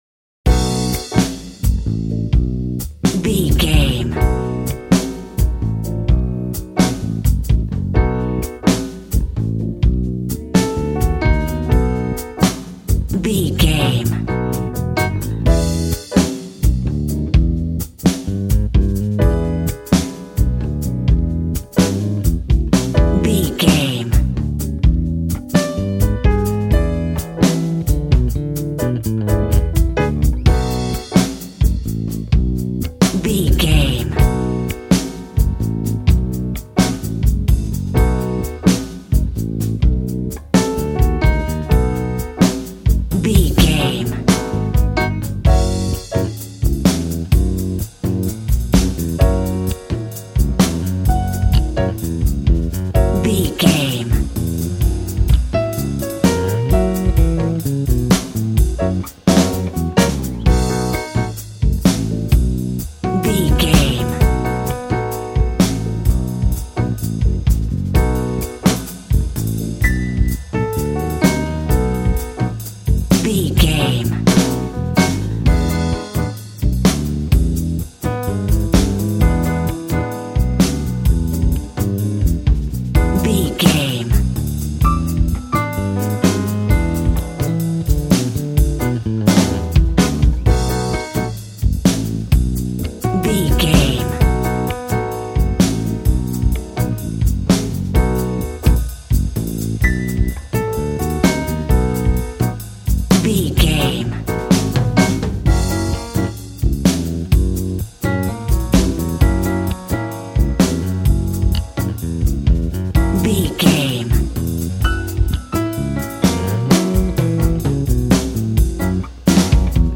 Aeolian/Minor
G♭
sad
mournful
bass guitar
electric guitar
electric organ
drums